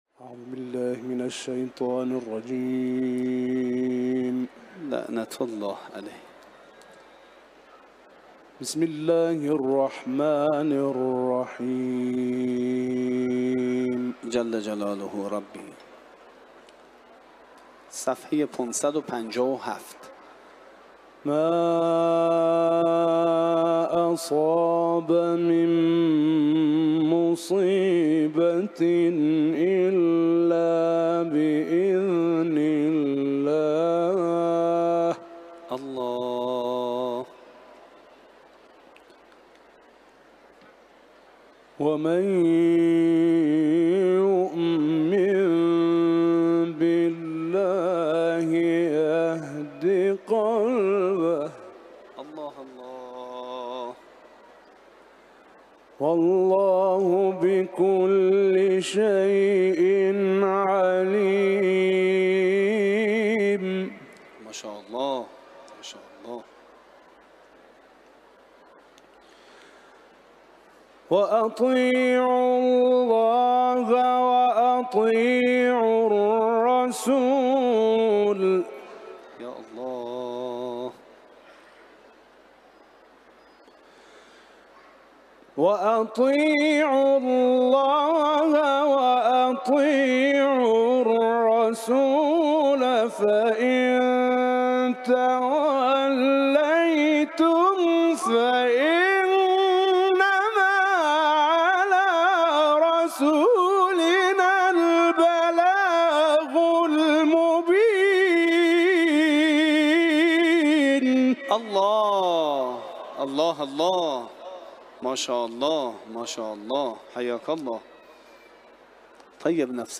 تلاوت قرآن ، سوره تغابن